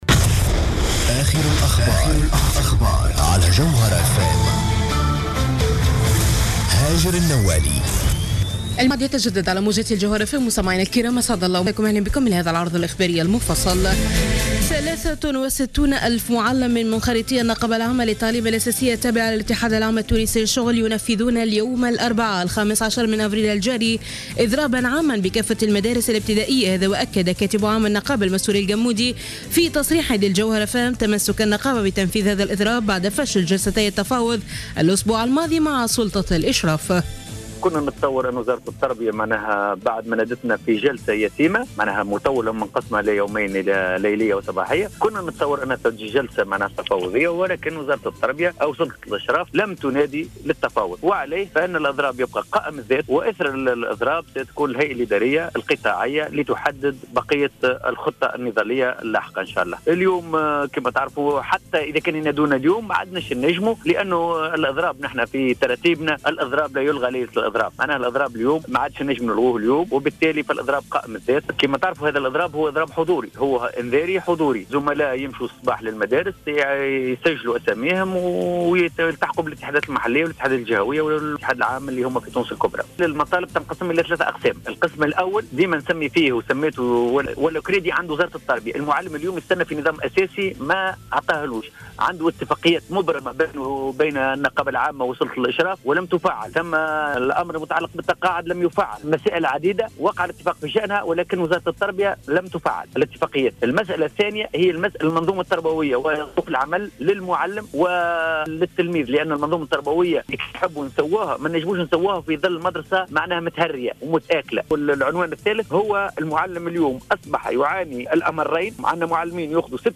نشرة أخبار منتصف الليل ليوم الإربعاء 15 أفريل 2015